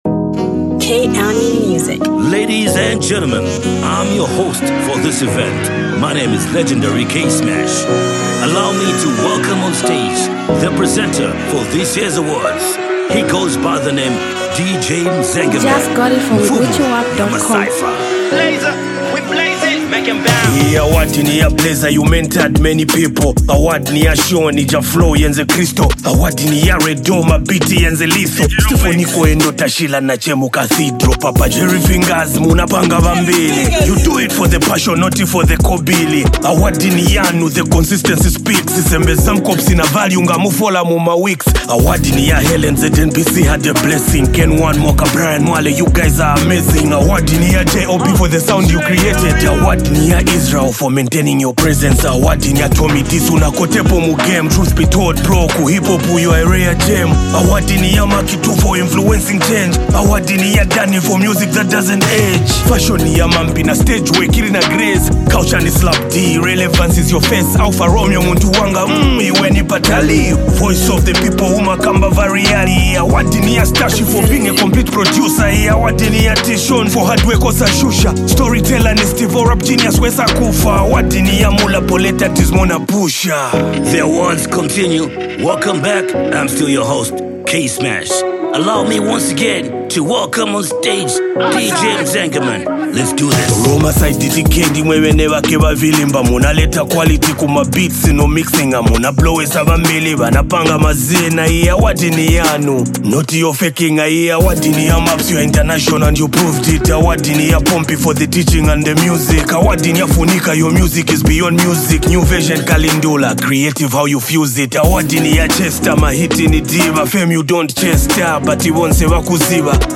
Zambian Mp3 Music
street anthem